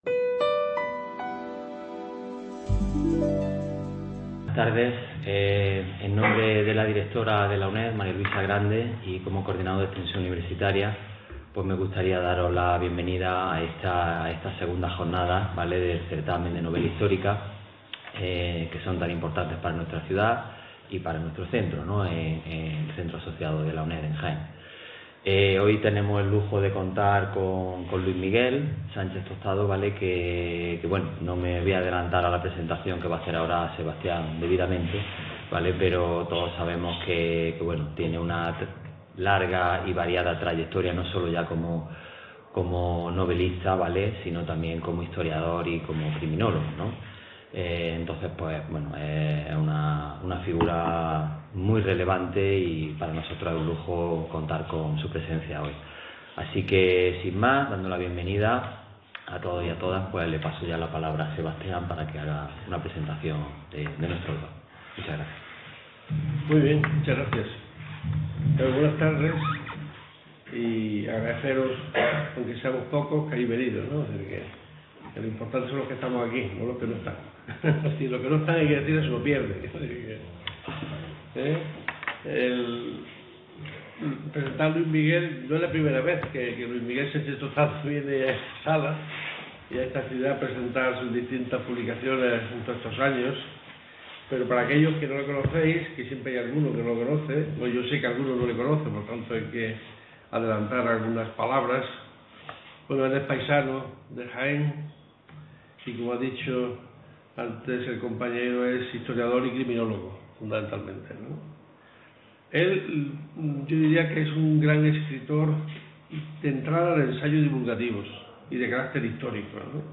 X edición del Certamen Internacional de Novela Histórica "Ciudad de Úbeda"